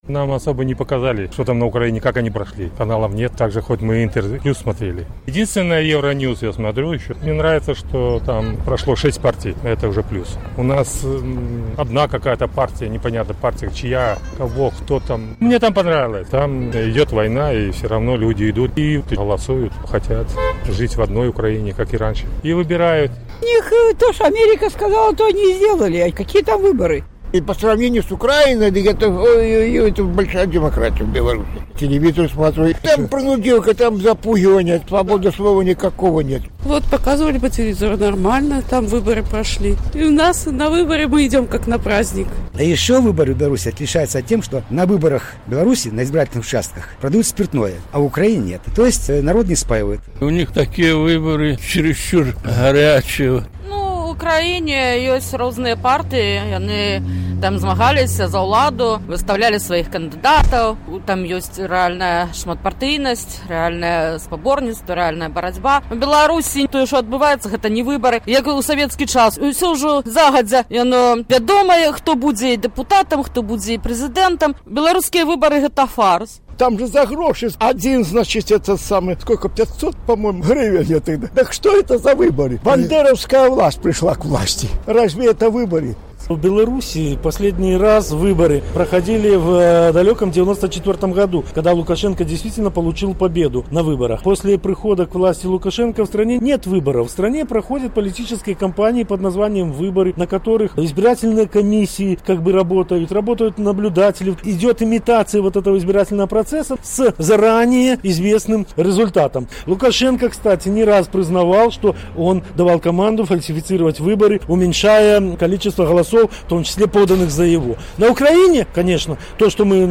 На гэтае пытаньне адказваюць жыхары Гомеля